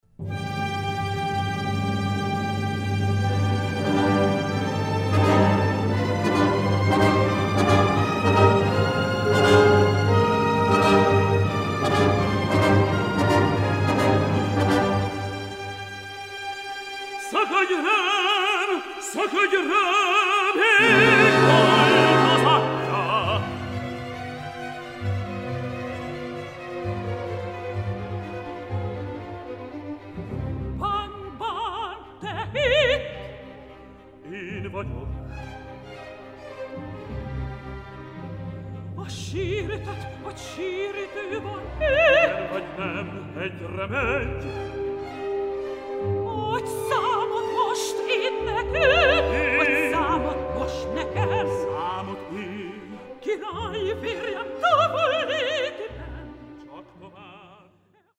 No.9 Duetto
HI: CD quality (192 kbps) mp3, max.1 min.